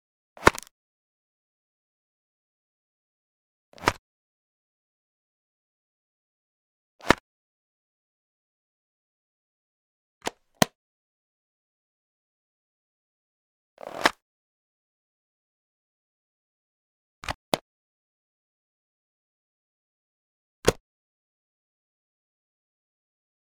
household
Computer Protective Plastic Computer Disk Case Open